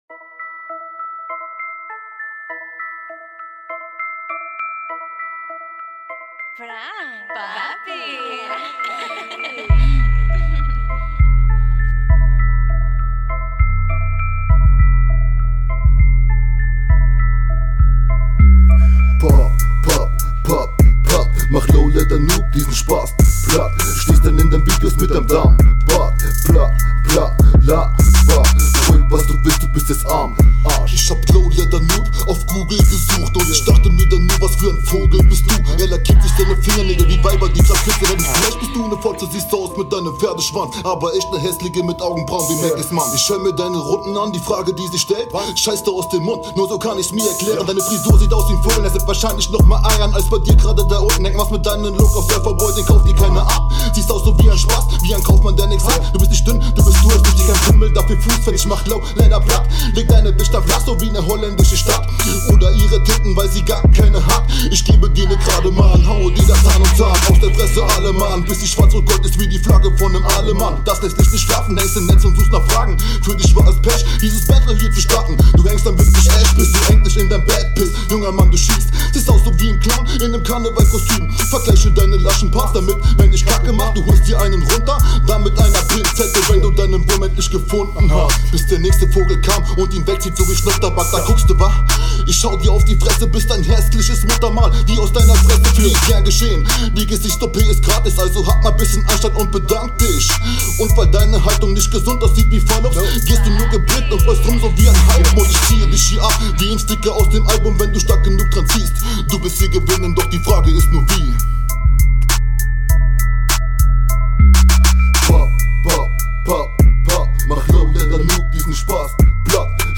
die Hook am Anfang hört sich bisschen komisch an mit dem Stimmeinsatz. da fehlt dir …